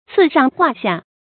刺上化下 cì shàng huà xià 成语解释 讽谕国君，教化下民。